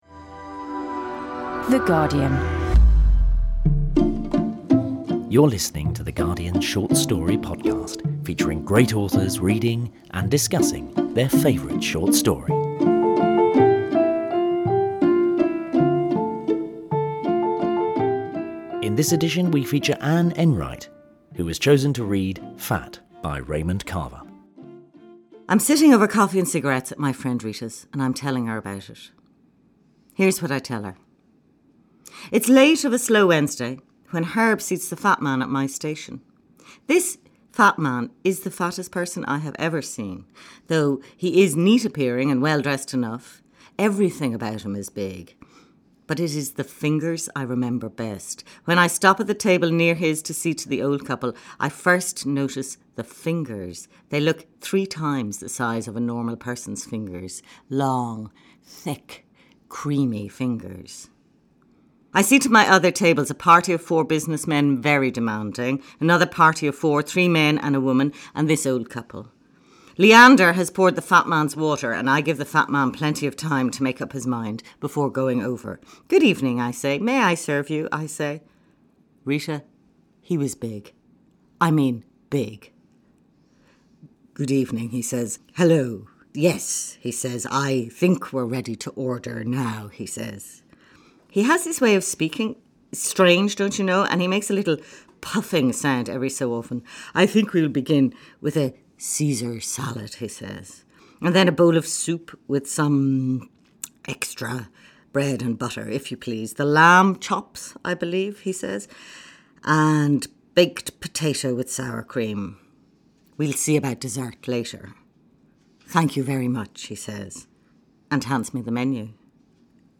Three Raymond Carver Stories, Read by Richard Ford, Anne Enright, and David Means